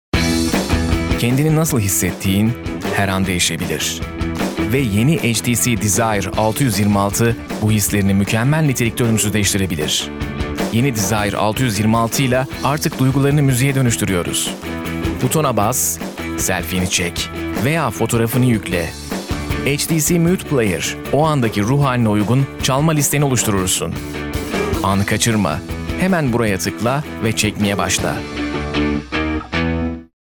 Sprechprobe: Industrie (Muttersprache):